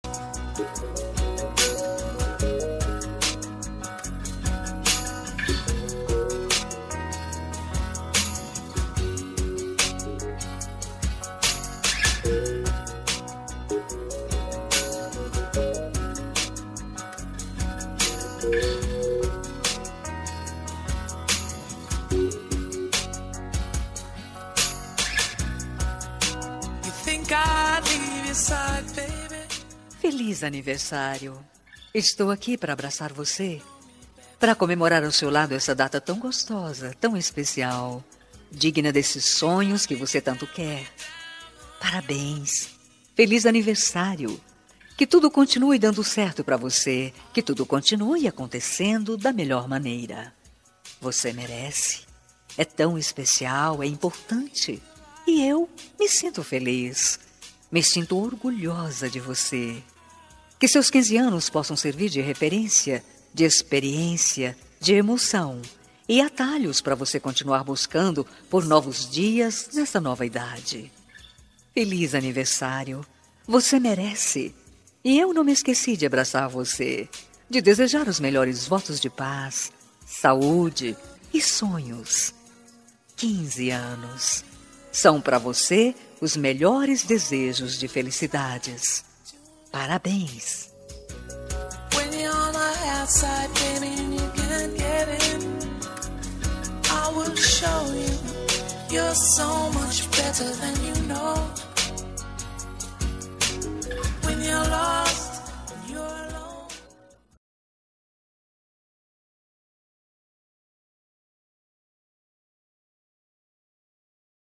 Aniversário de 15 anos – Voz Feminina – Cód: 33374